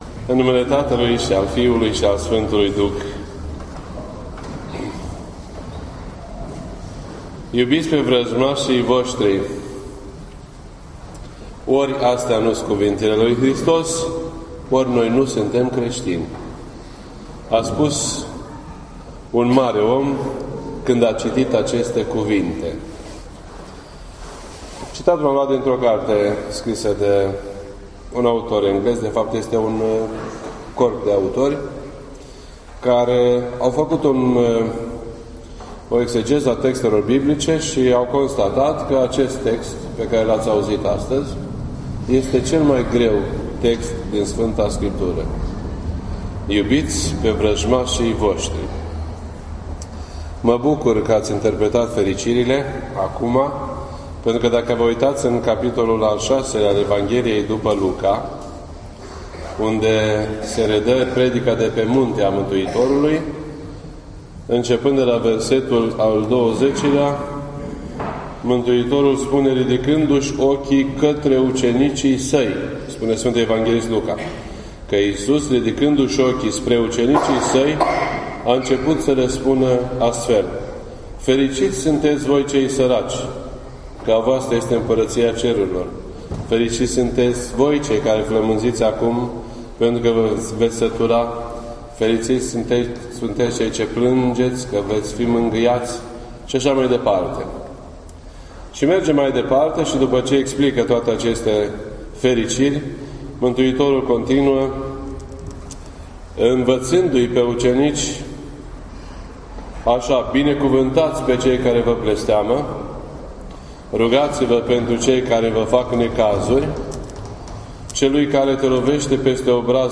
This entry was posted on Sunday, October 2nd, 2016 at 10:29 AM and is filed under Predici ortodoxe in format audio.